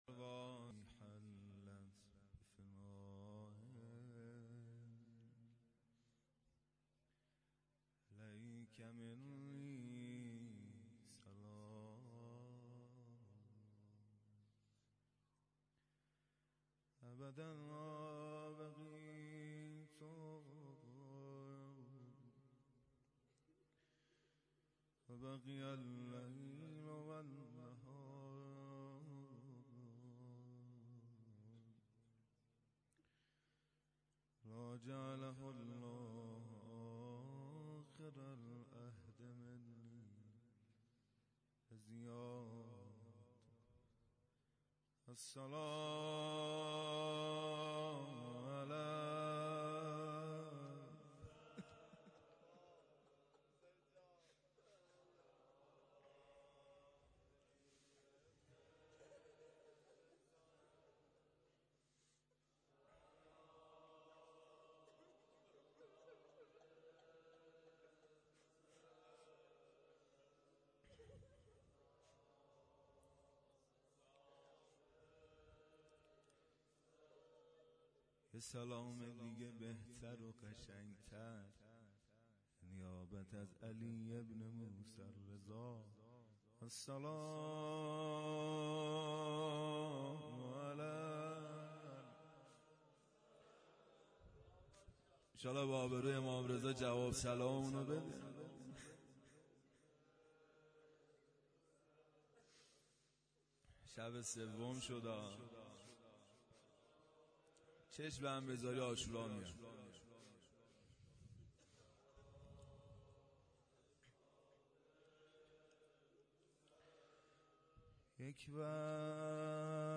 روضه حضرت رقیه .س.